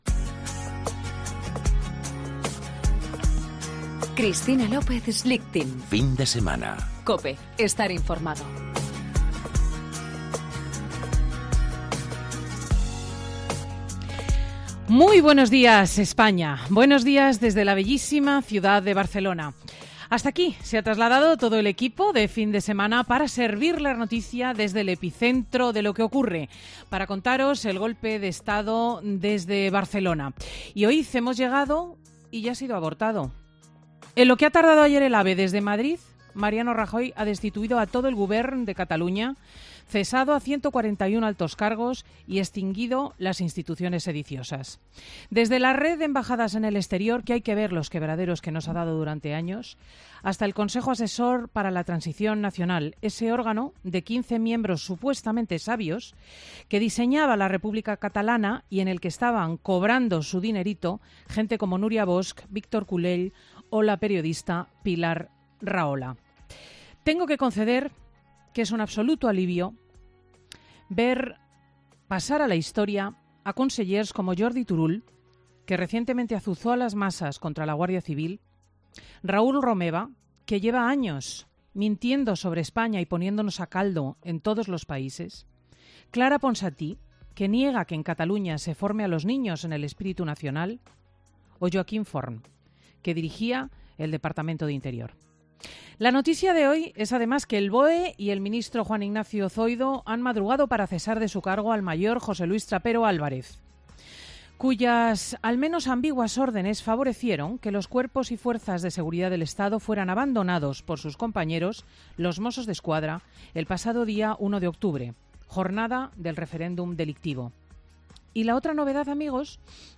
AUDIO: El editorial de Cristina López Schlichting a las 10.00 horas de este sábado desde Barcelona.